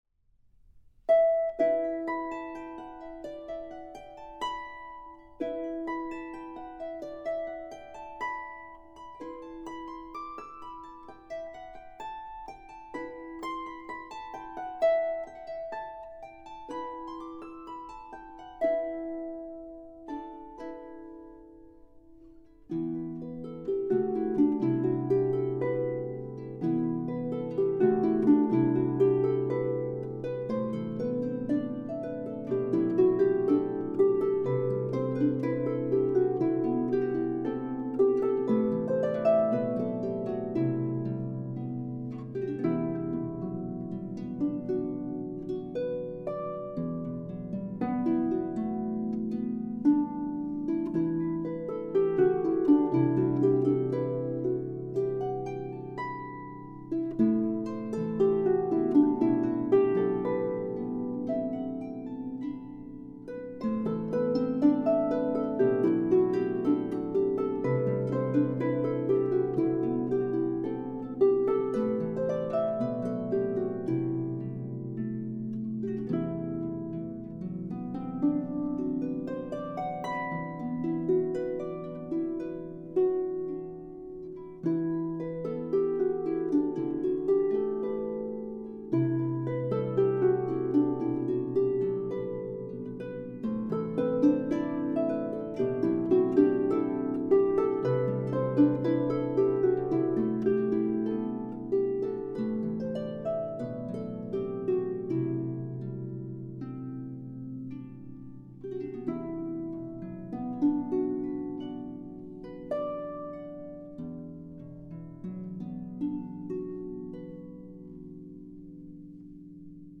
traditional Christmas carol